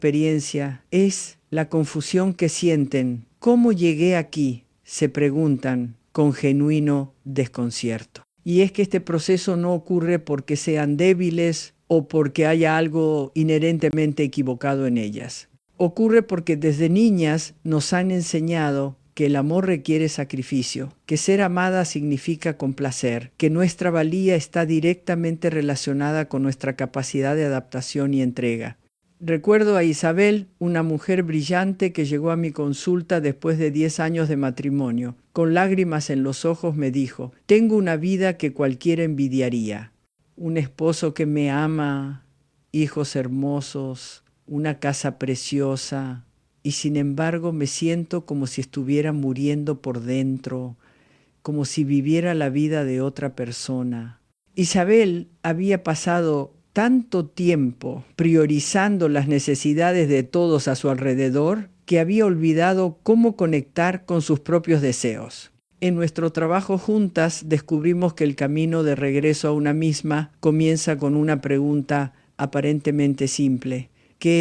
ref_female_es.wav